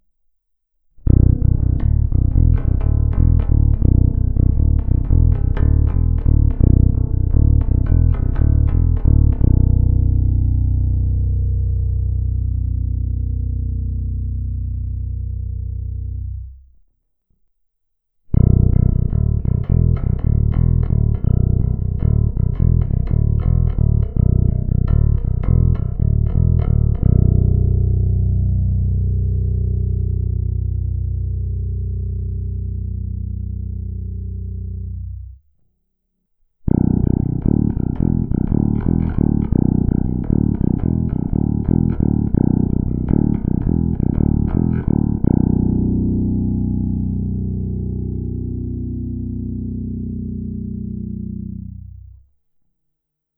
Není-li uvedeno jinak, následující nahrávky jsou provedeny rovnou do zvukové karty, jen normalizovány, jinak ponechány bez úprav.
Struna H ve stejném pořadí jako výše